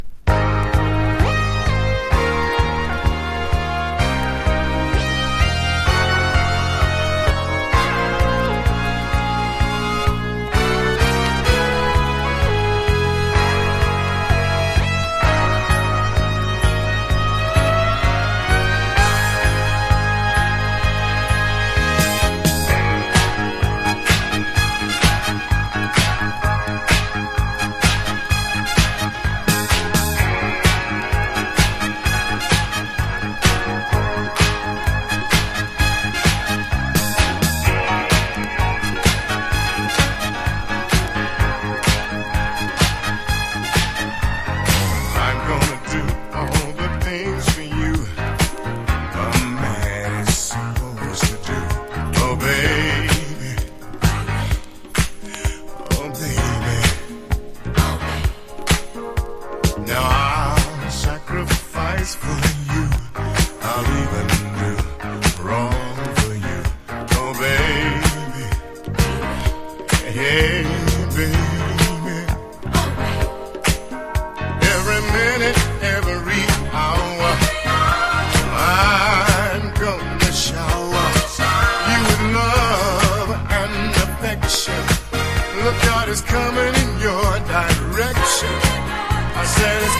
RARE GROOVE# FUNK / DEEP FUNK# DISCO